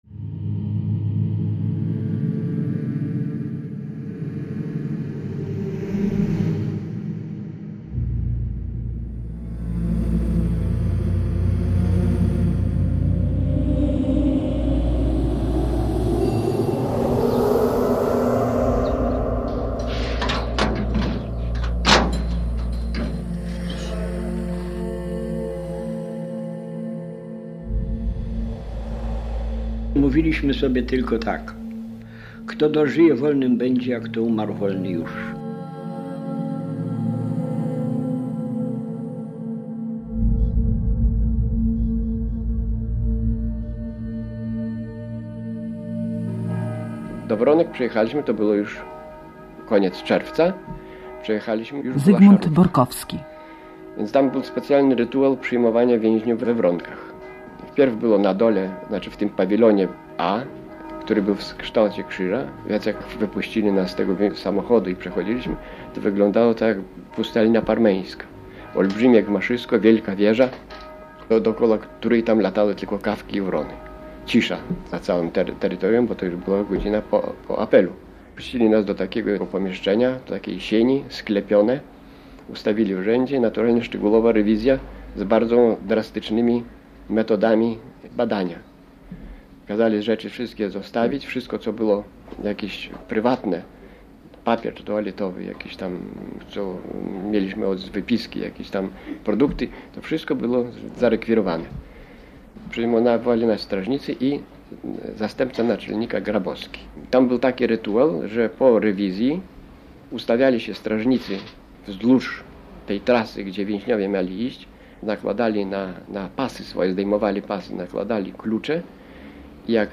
Żołnierze wyklęci - posłuchaj reportażu